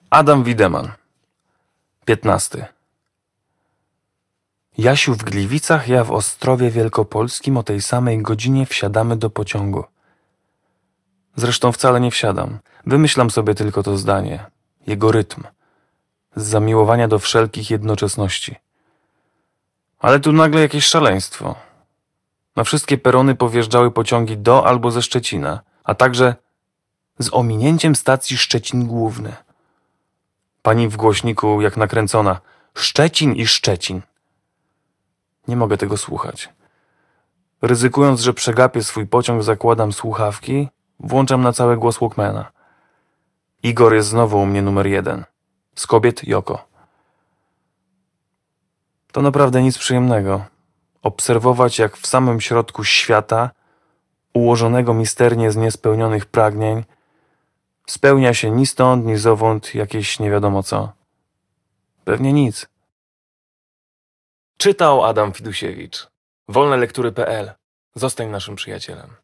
Wiersz Liryka
Audiobook